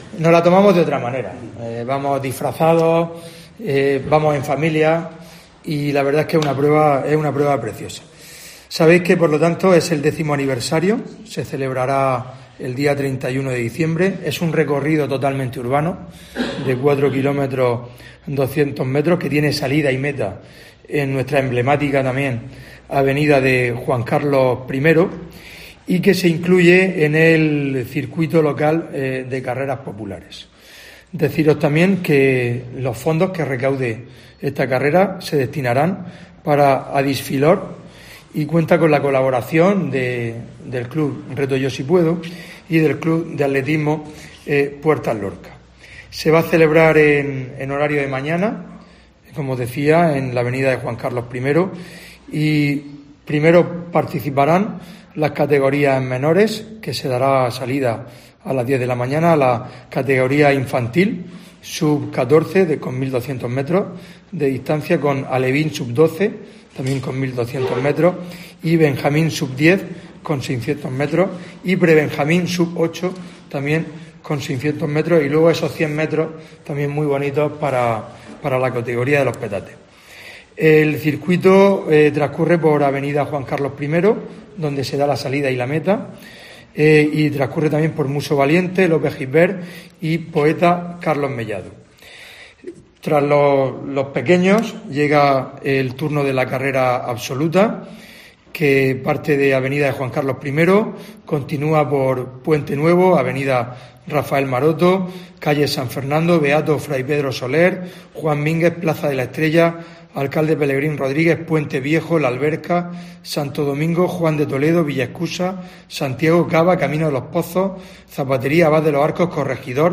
Fulgencio Gil, alcalde de Lorca